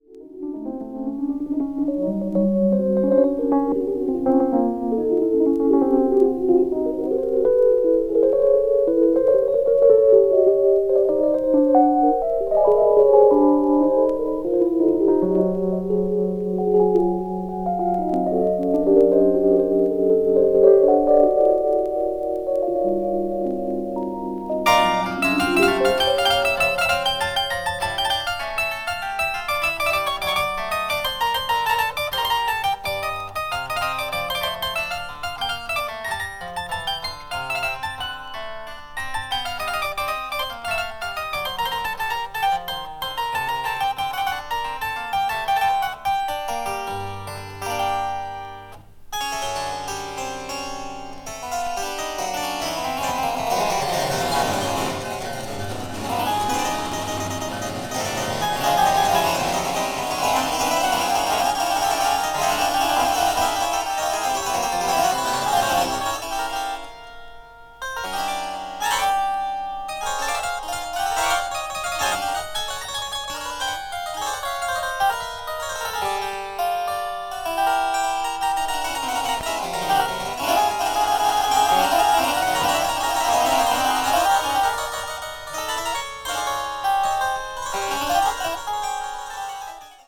media : EX/EX-(わずかにチリノイズが入る箇所あり)